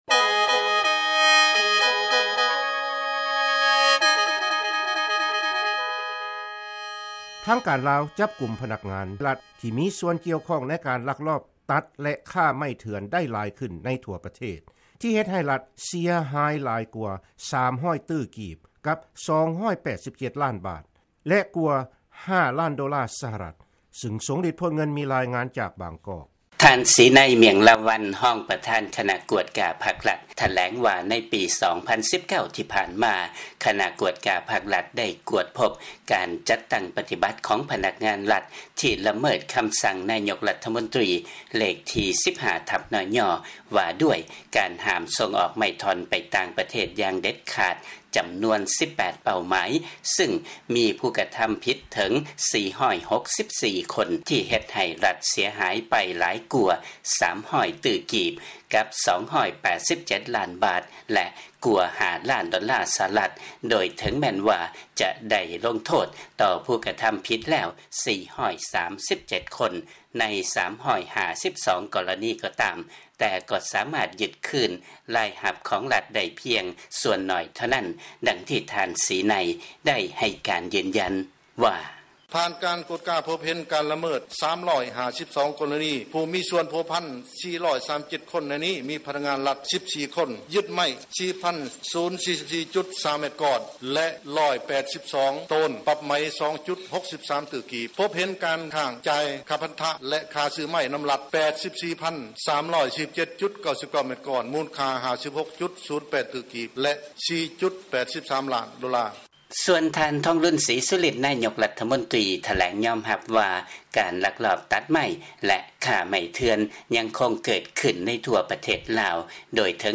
ຟັງລາຍງານ ທາງການລາວ ຈັບກຸມພະນັກງານລັດ ທີ່ກ່ຽວຂ້ອງ ໃນການລັກລອບຕັດ ແລະ ຄ້າໄມ້ເຖື່ອນໄດ້ຫຼາຍຂຶ້ນ